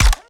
GUNAuto_RPU1 B Fire_02_SFRMS_SCIWPNS.wav